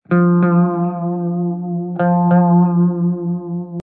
descargar sonido mp3 guitarra